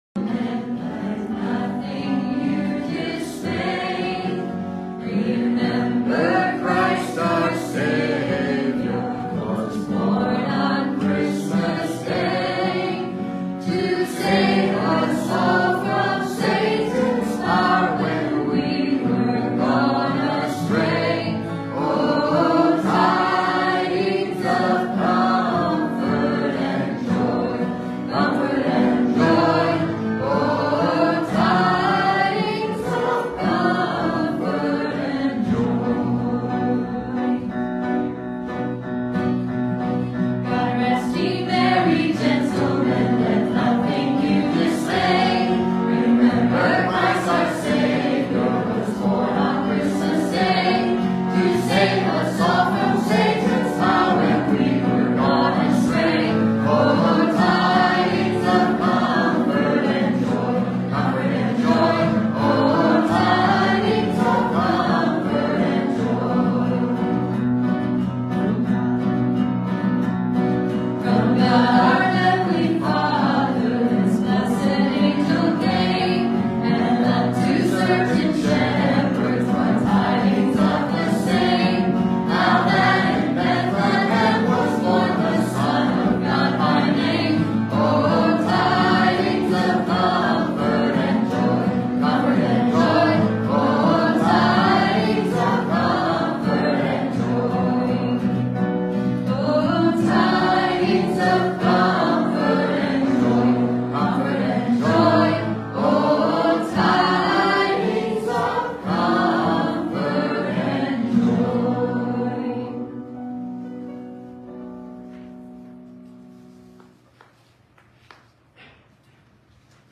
Passage: Luke 1:5-25 Service Type: Sunday Morning « To Those Who Love God The Miracle of Christmas